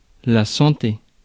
Ääntäminen
France: IPA: [sɑ̃.te]